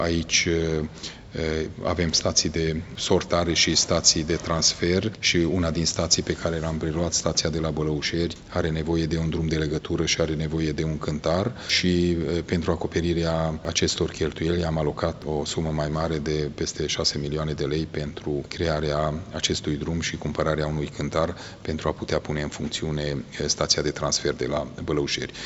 Șeful administrației județene Mureș, Peter Ferenc: